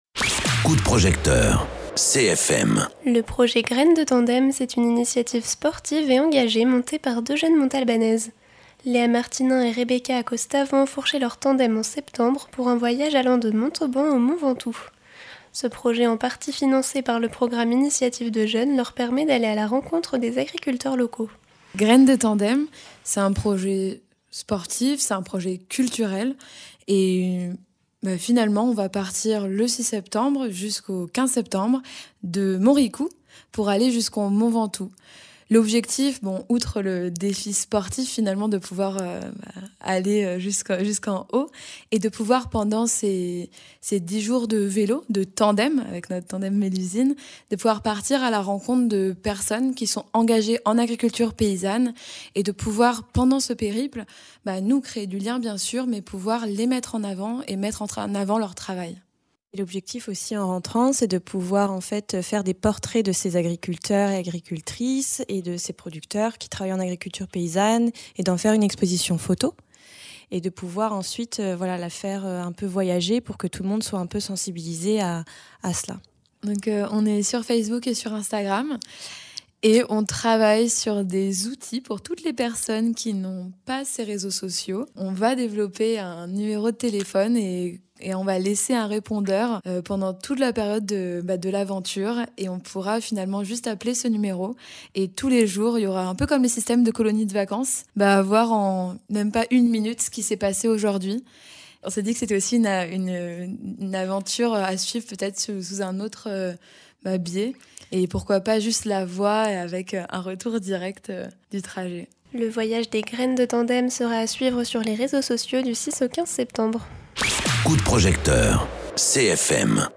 Invité(s)